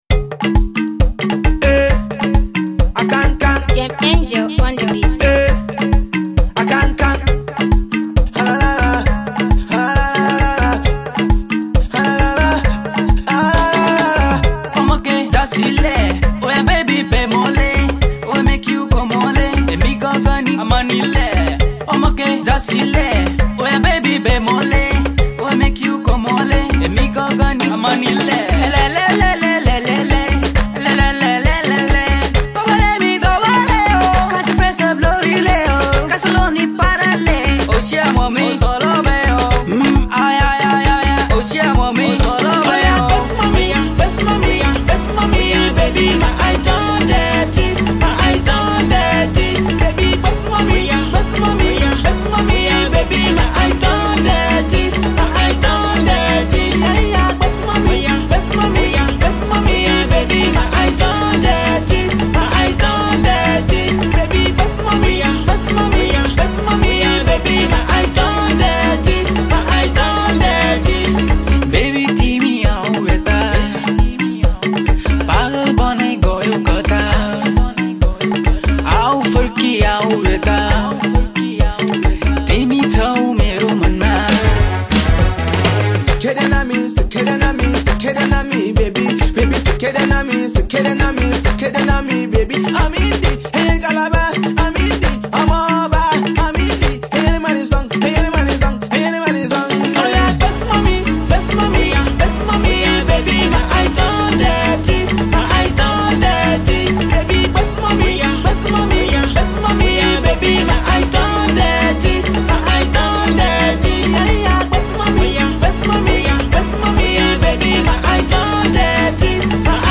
blend of Afro pop